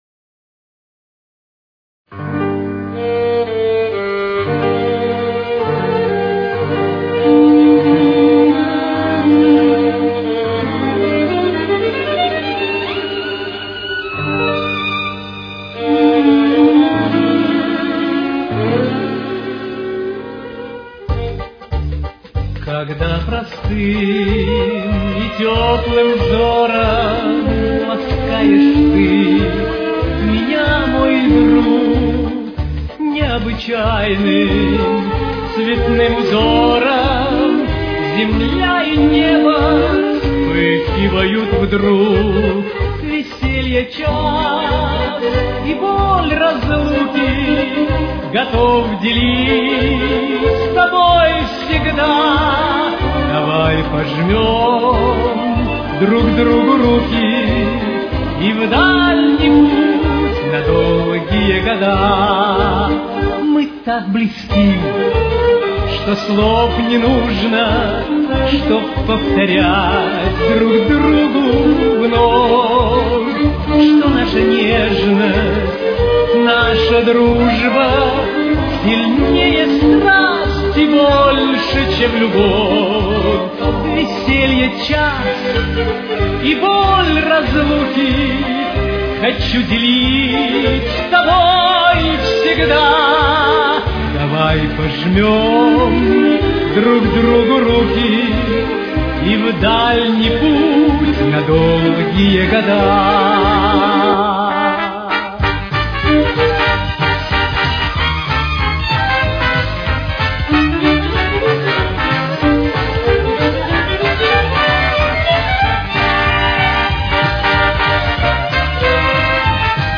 с очень низким качеством (16 – 32 кБит/с)
Темп: 96.